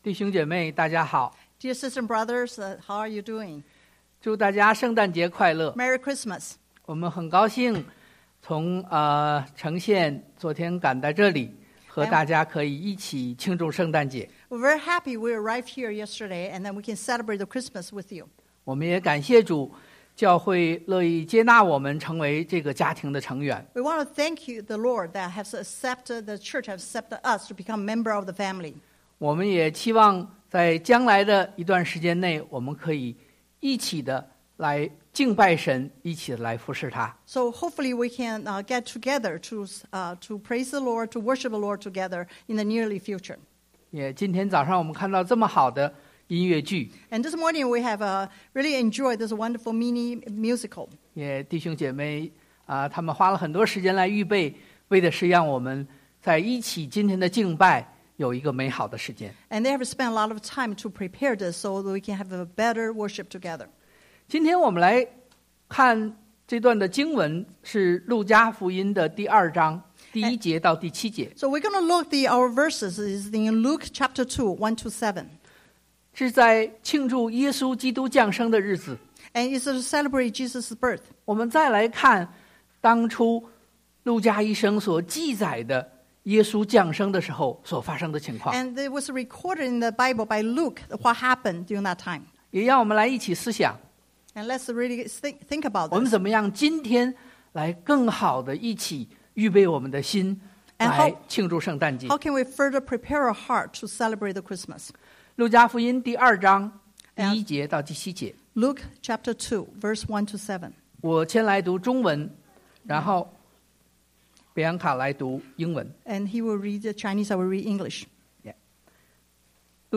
2018-12-23 Christmas Worship Service